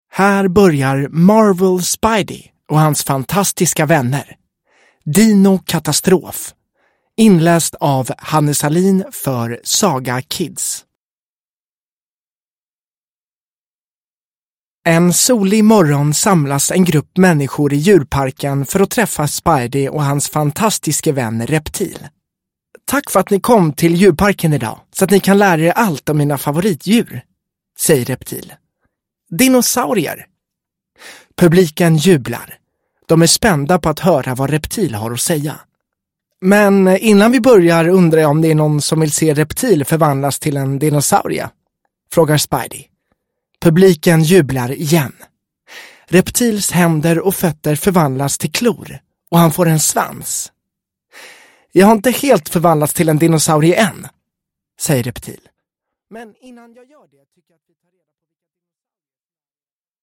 Spidey och hans fantastiska vänner – Dino-katastrof – Ljudbok